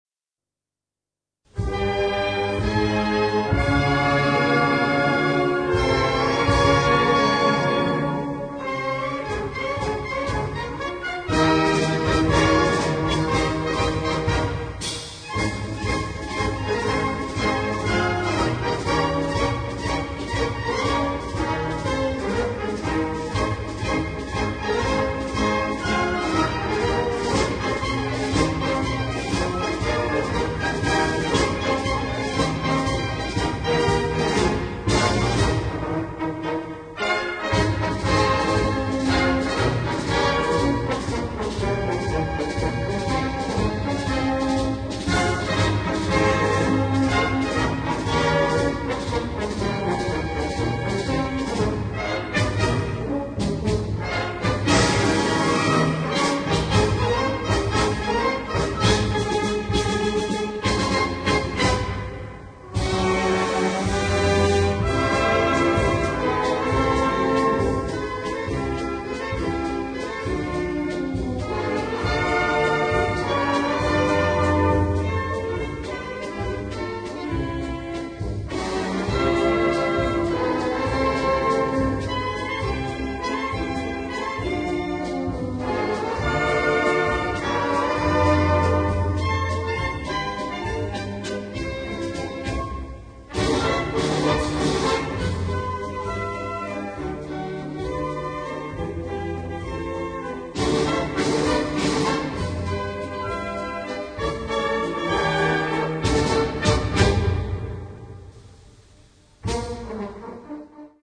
Gattung: Potpourri
A4 Besetzung: Blasorchester PDF